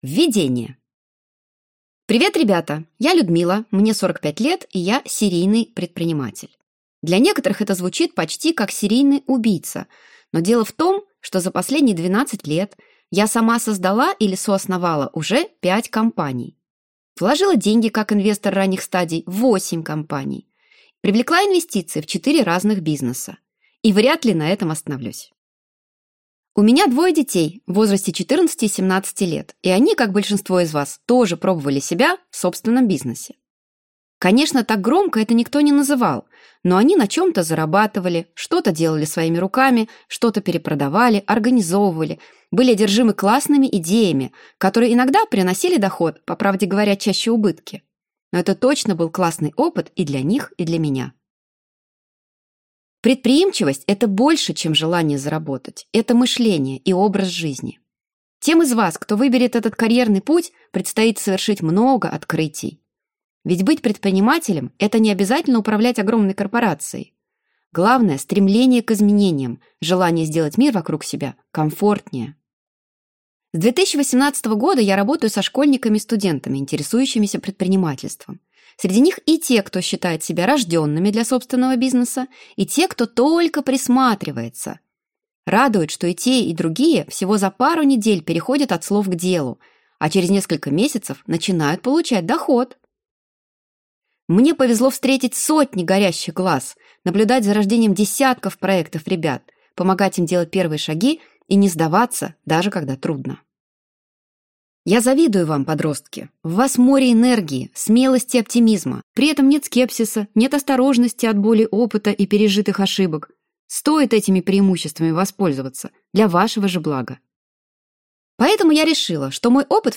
Слушать аудиокнигу Почти взрослый бизнес. 10 шагов к своему делу полностью